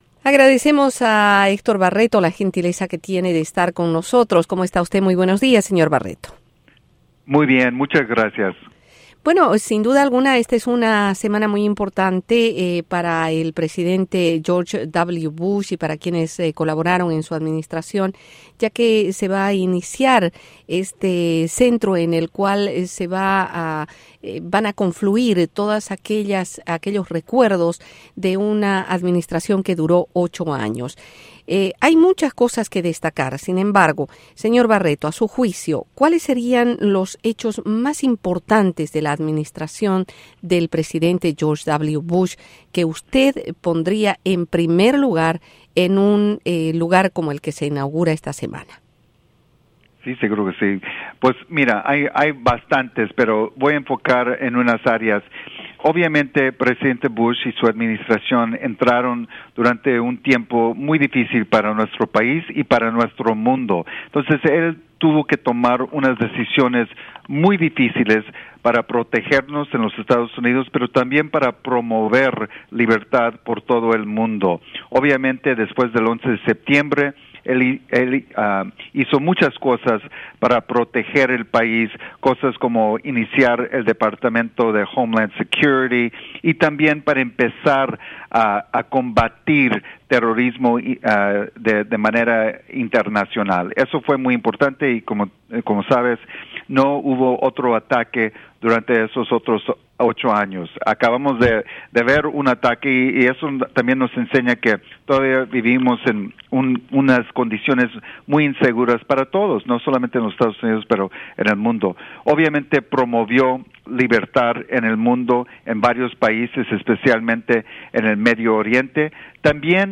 Entrevista exclusiva: Héctor Barreto